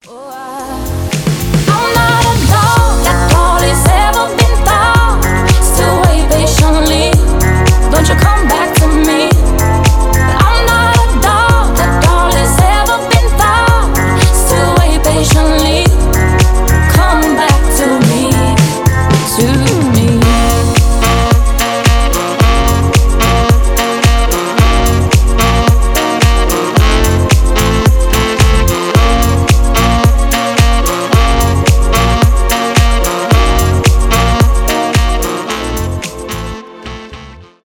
deep house
танцевальные , поп